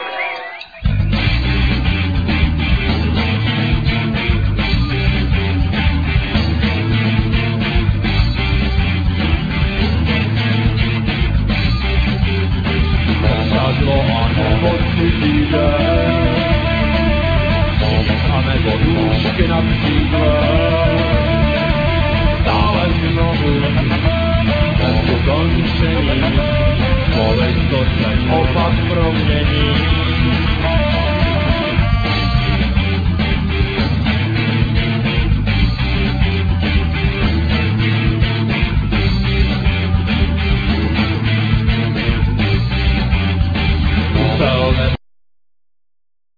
Vocals,Guitar,Keyboard
Saxophone,Vocals
Bass,Vocals
Drums